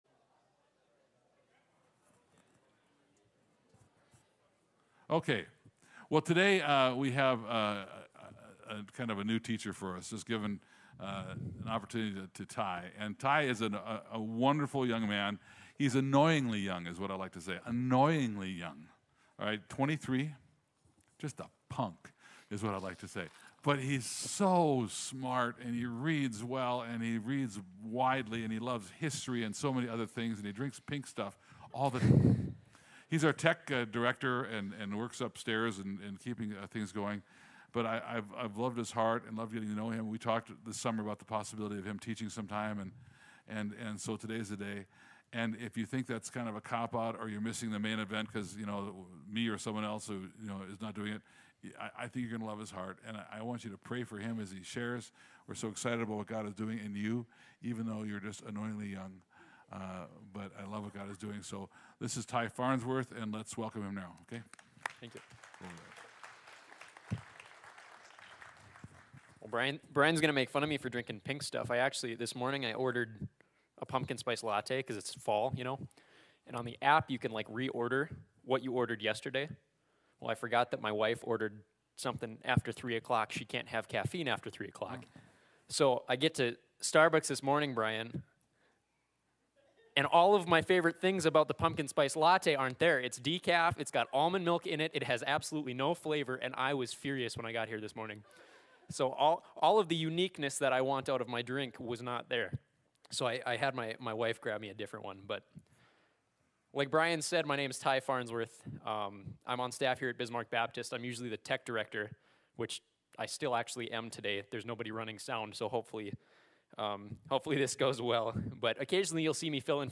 United-in-Christ-September-25-Sermon.mp3